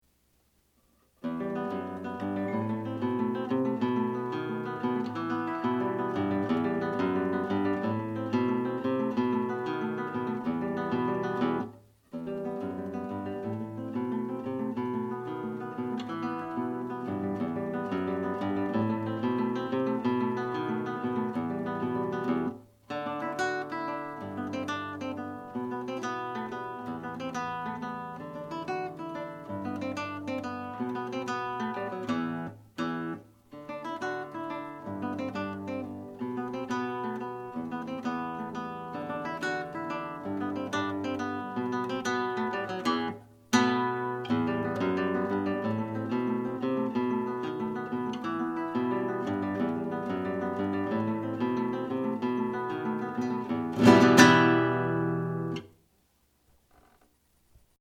Un pÃ˛ di Sud America....in versione didattica